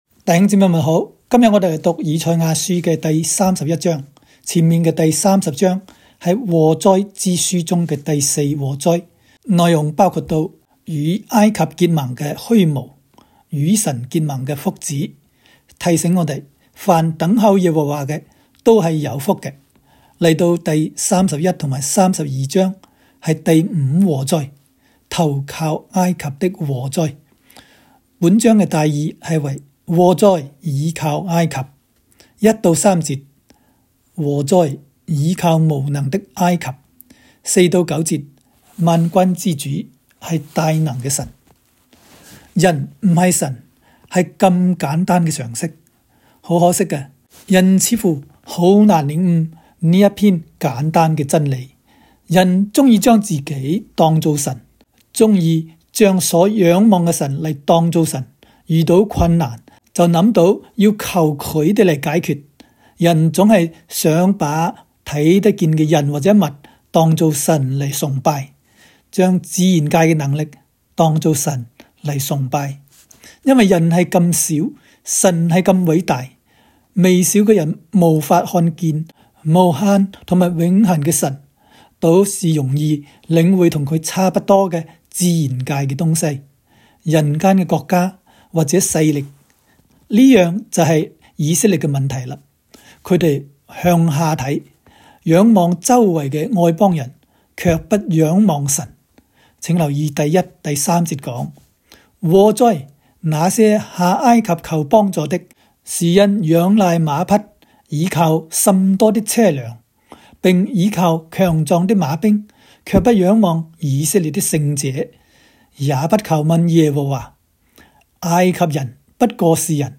赛31（讲解-粤）.m4a